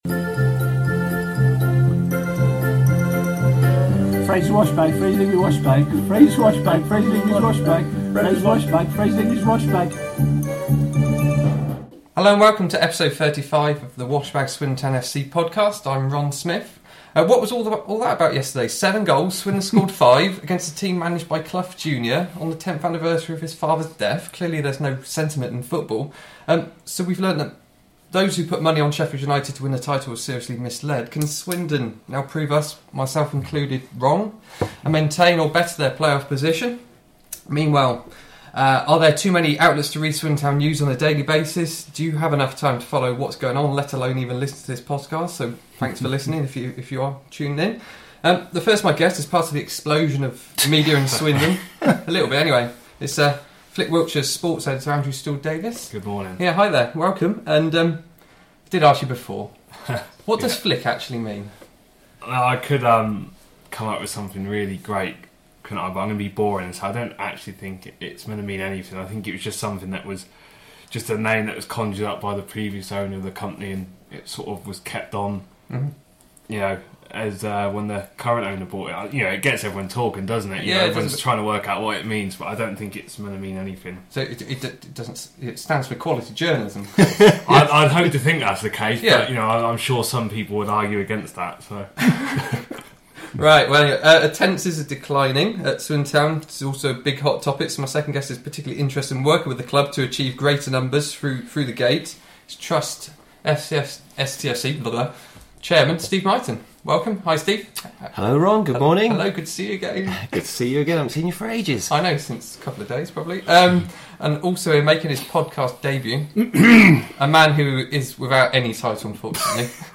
Attendances, or lack of numbers through the game, is the hot top of the moment, which the four also discuss.